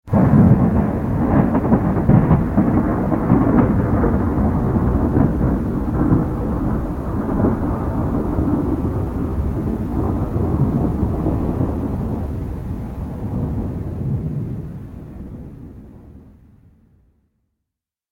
دانلود آهنگ طوفان 9 از افکت صوتی طبیعت و محیط
جلوه های صوتی
دانلود صدای طوفان 9 از ساعد نیوز با لینک مستقیم و کیفیت بالا